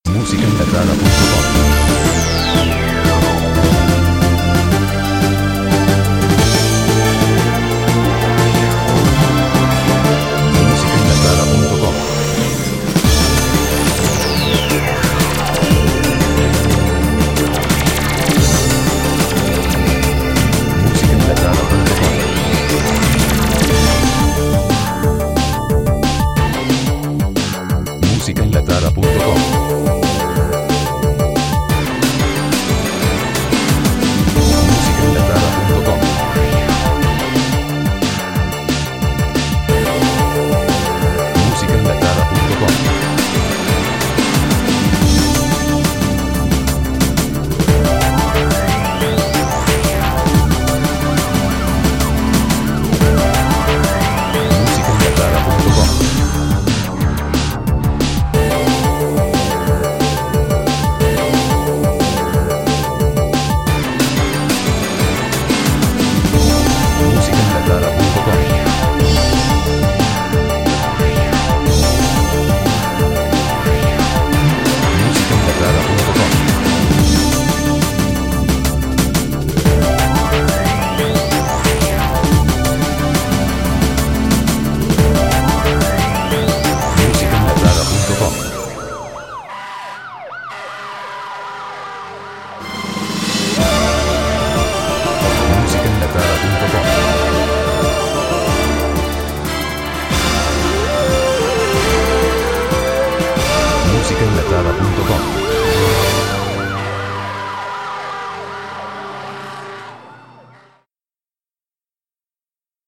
Estilo: Sinfónico Electrónico